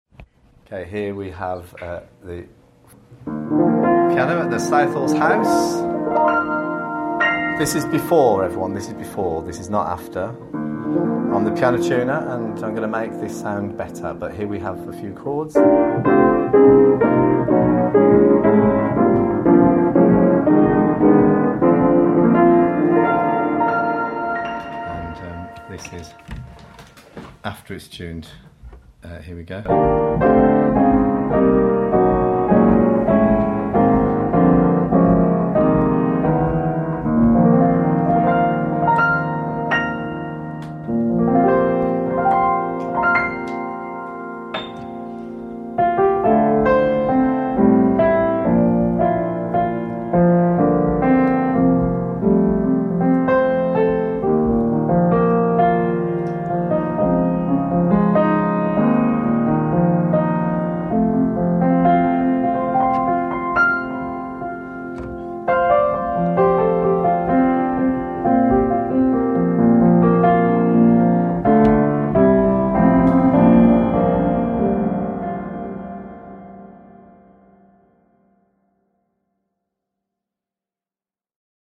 We made this audio to demonstrate the contrast between a piano before it is tuned and after our work is complete.